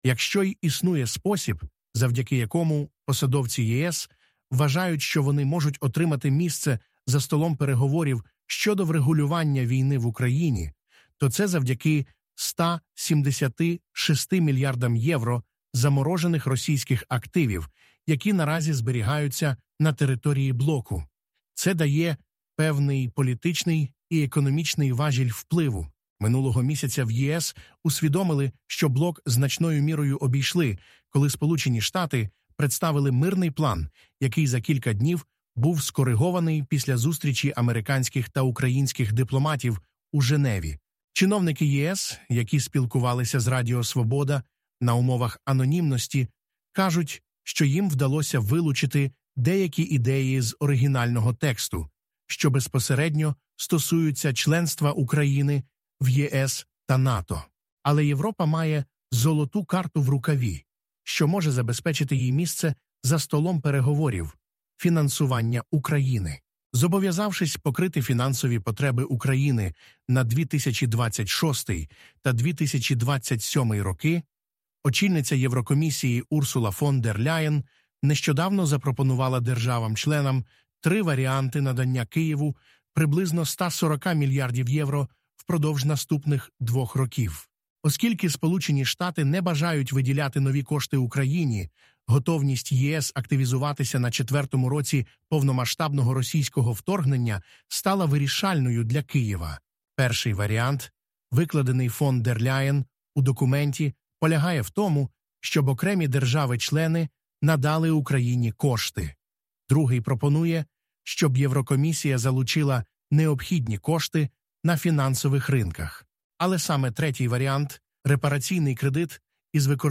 Ви можете прослухати цей текст, ми озвучили його за допомогою штучного інтелекту: